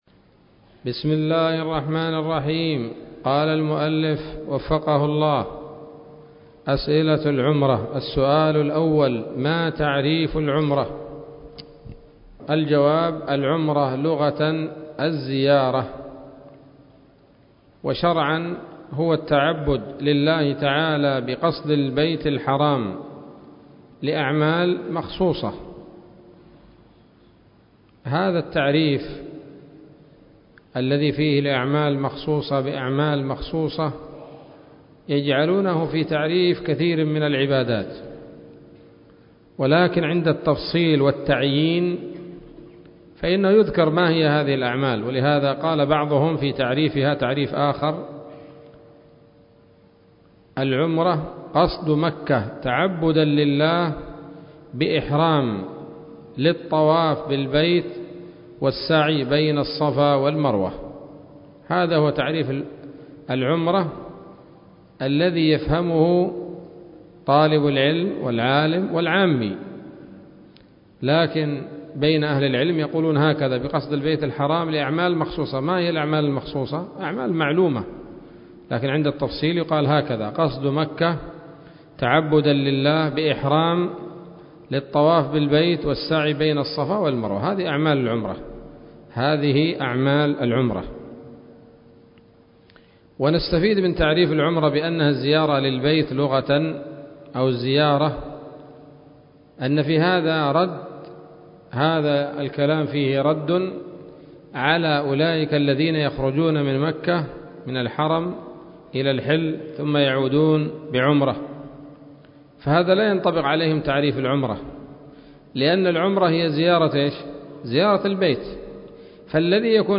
الدرس الرابع من شرح القول الأنيق في حج بيت الله العتيق